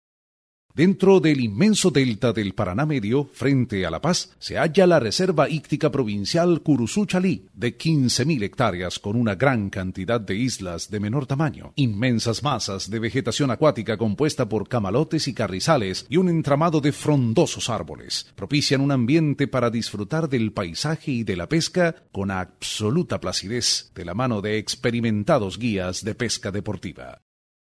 Sprecher spanisch. (Südamerika) weitere Sprachen: Englisch (Britisch und Nordamerika), Italienisch.
spanisch Südamerika
Sprechprobe: eLearning (Muttersprache):